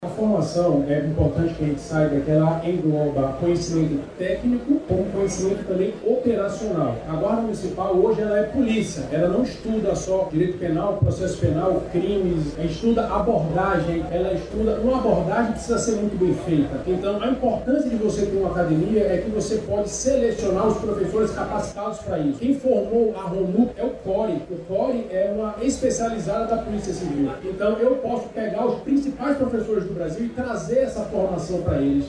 O secretário municipal de Segurança Pública e Defesa Social, Alberto Siqueira, explica que a Instituição vai contar com formação especializada.